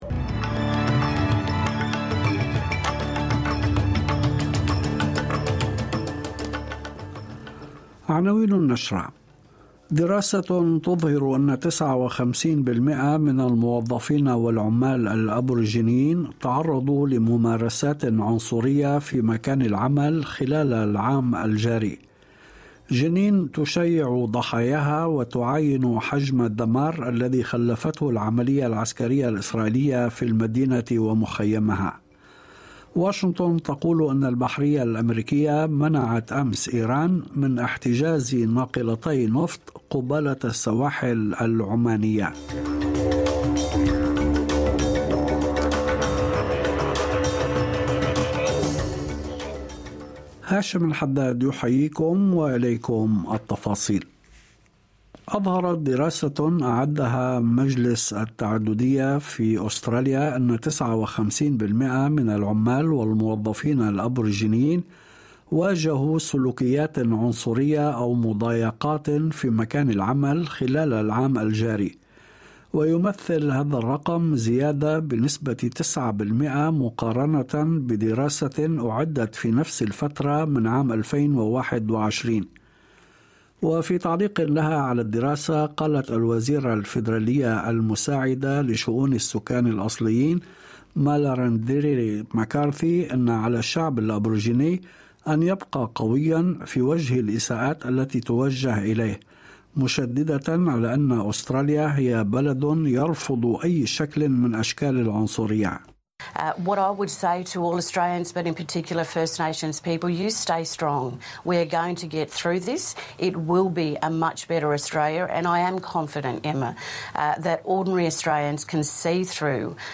نشرة أخبار المساء 06/07/2023